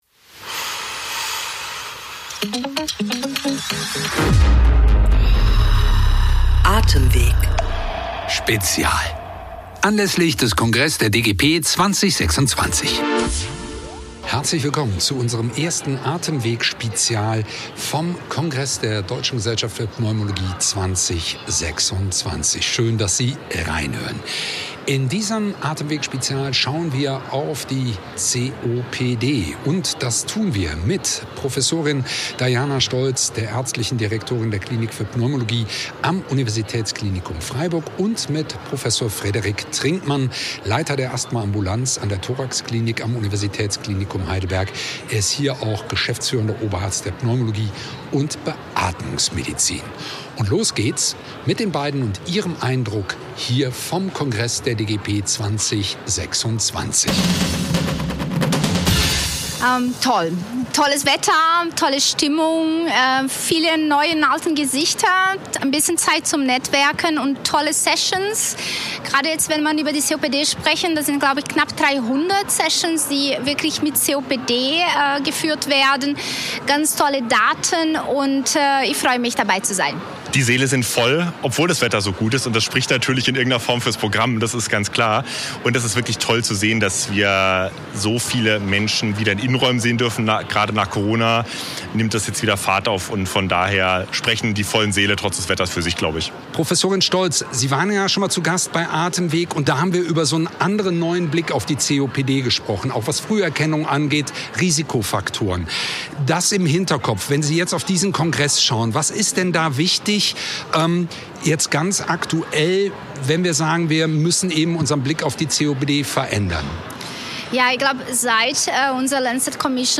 Wir berichten live vom 66. DGP-Kongress in München: In unserer dreiteiligen Podcastreihe sprechen wir mit renommierten Expert*innen über COPD, Asthma, sowie Idiopathische Lungenfibrose und Interstitielle Lungenerkrankungen.